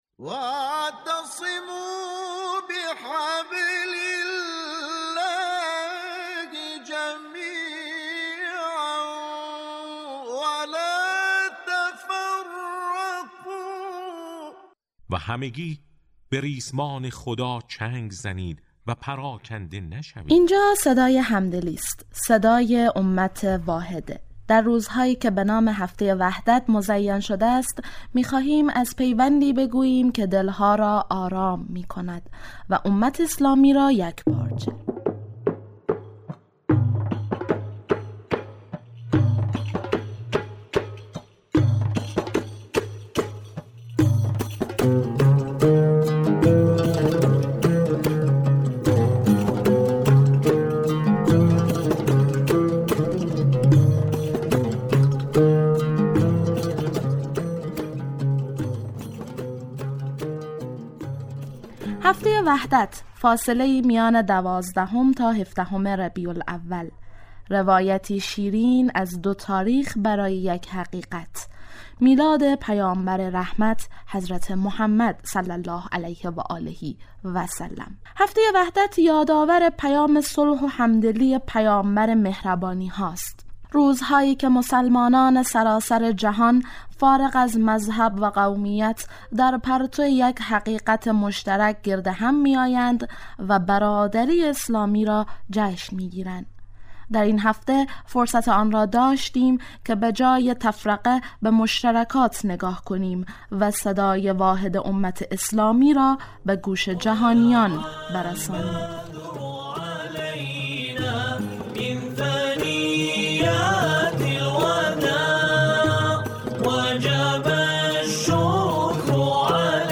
در هفته‌ای که به نام وحدت مزین شده، صداها یکی می‌شوند؛ از نغمه‌های روح‌نواز و سخنان کارشناسان تا تجربه‌ها و دل‌گفته‌های مردم. مستند رادیویی